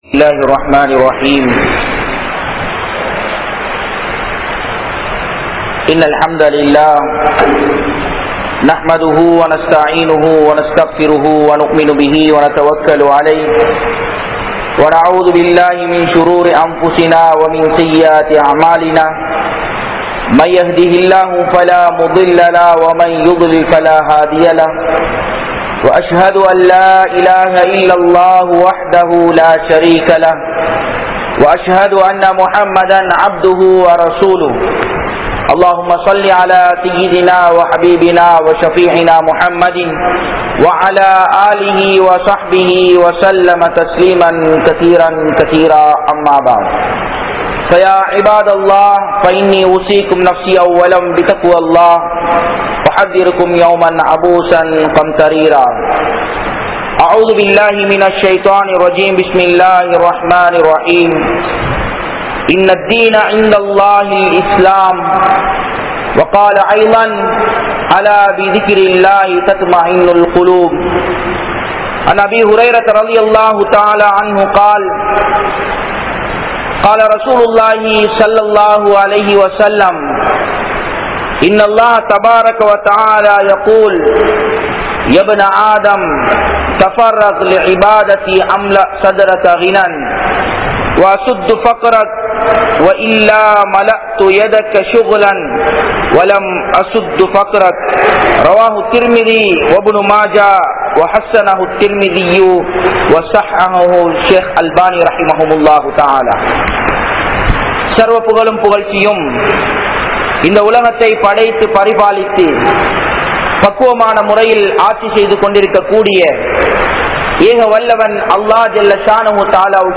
Nimmathi Illaatha Manitharhal (நிம்மதி இல்லாத மனிதர்கள்) | Audio Bayans | All Ceylon Muslim Youth Community | Addalaichenai